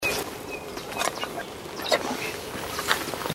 Spotted Nothura (Nothura maculosa)
Life Stage: Adult
Detailed location: Reserva Natural Laguna Guatraché
Condition: Wild
Certainty: Recorded vocal
Inambu-comun.mp3